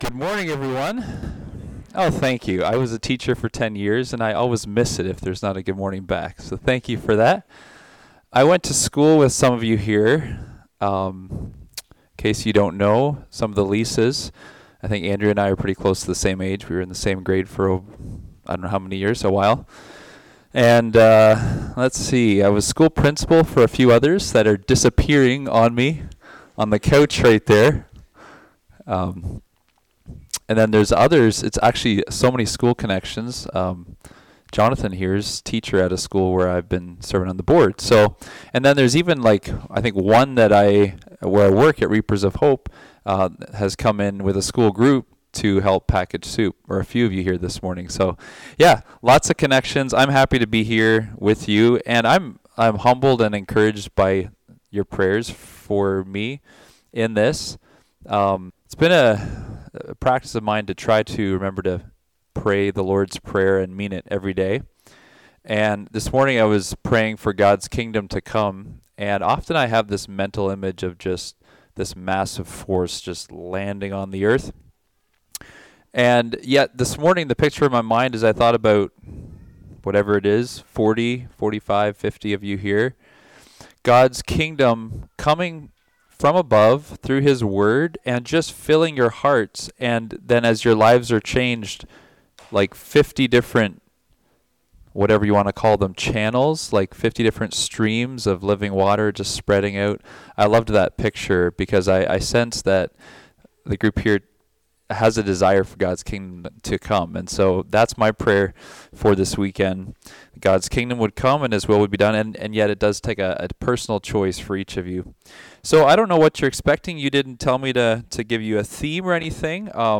Topic: Life Choices Service Type: Youth Meetings